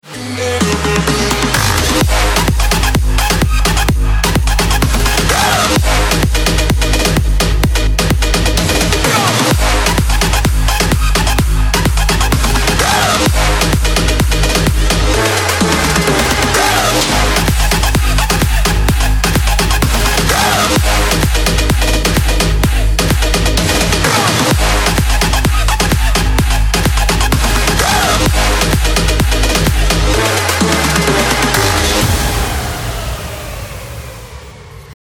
Бодрая музыка на будильник